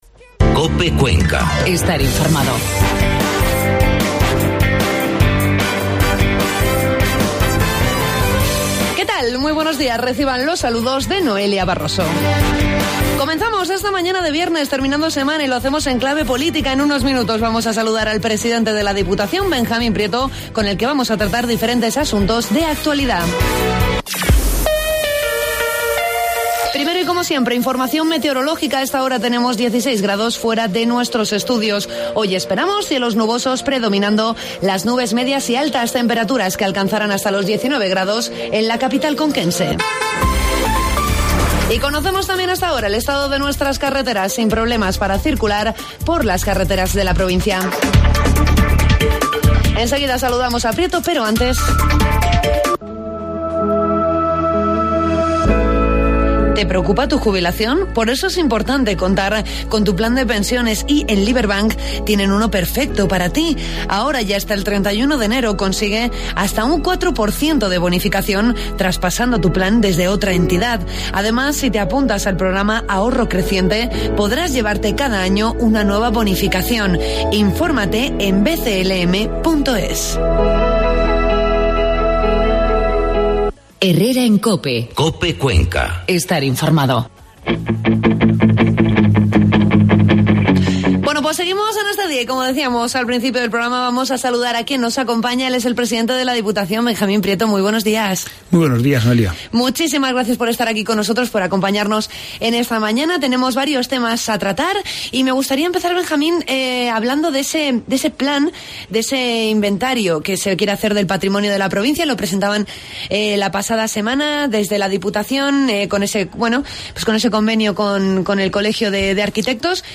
Entrevista al presidente de la Diputación, Benjamín Prieto.